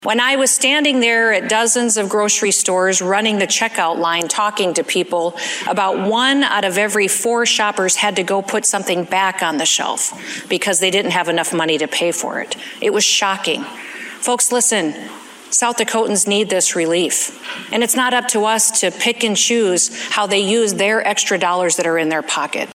From the start of her State of the State Address today (Jan. 10, 2023), Republican South Dakota Governor Kristi Noem says the state’s economy is good, it has a surplus, and she wants to lower taxes.